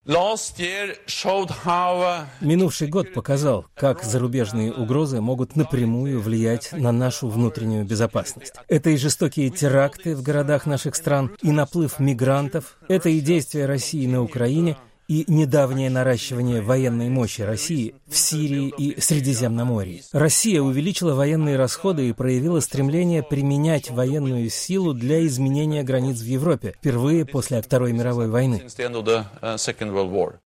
Говорит генеральный секретарь НАТО Йенс Столтенберг